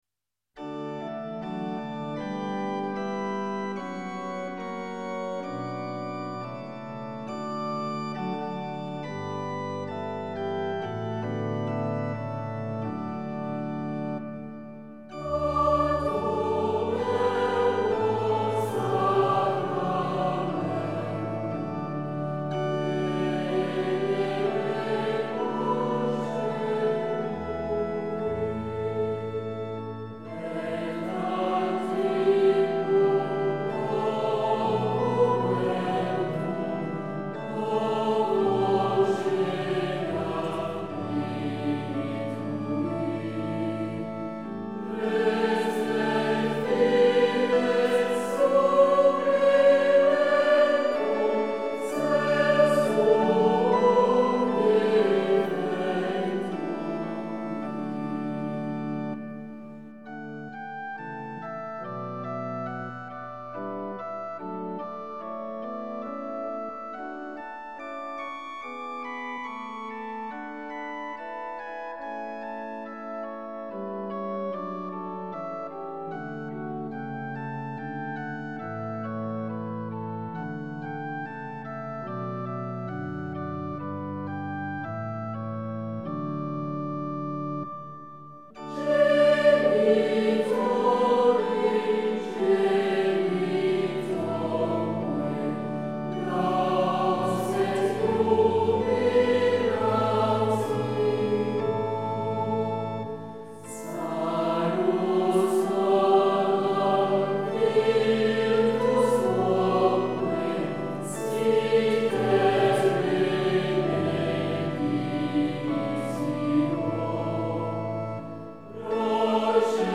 CHANTS GRÉGORIENS